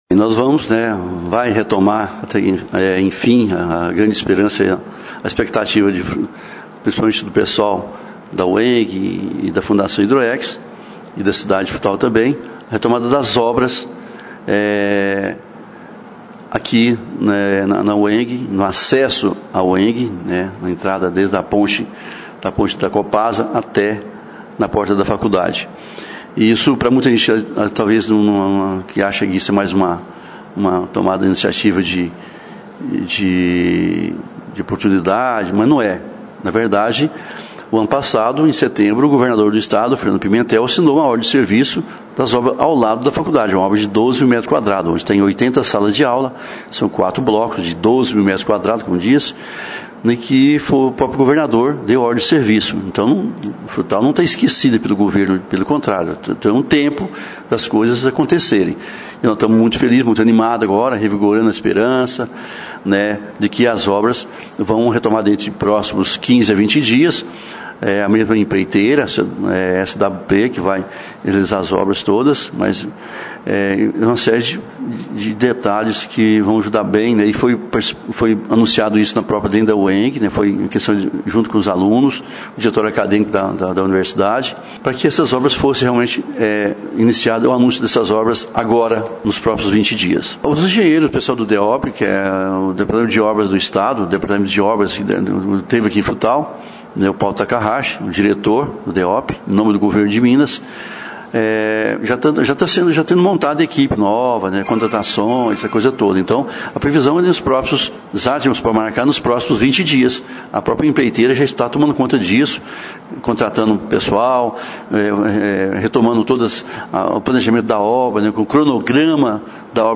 O Governo de Minas Gerais autorizou o reinício das obras do complexo HIDROEX-UEMG, com a pavimentação interna, conclusão de redes de esgoto, laboratórios e revitalização da biblioteca. (Clique nos players abaixo e ouça as entrevistas).